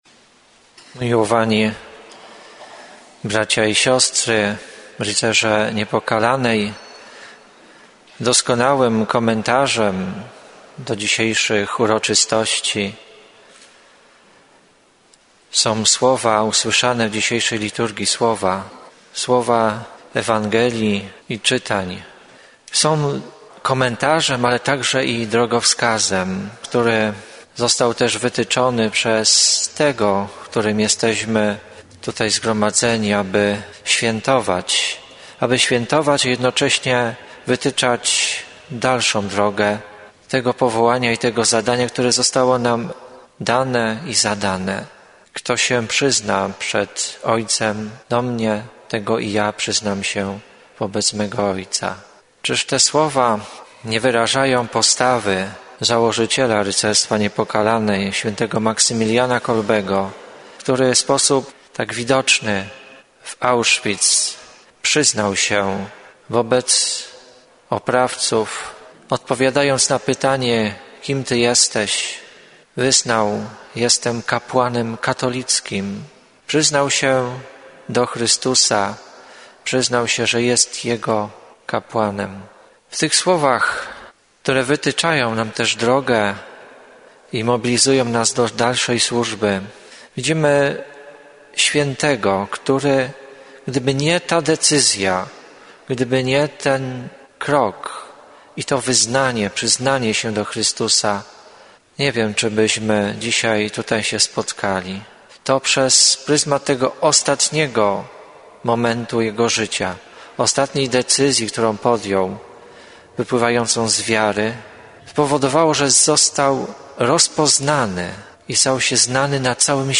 W sobotę, 19 października 2019 r., w Bazylice św. Franciszka z Asyżu w Krakowie odbyły się obchody 100-lecia Rycerstwa Niepokalanej w Polsce. W uroczystościach wzięli udział członkowie Stowarzyszenia z Polski południowej.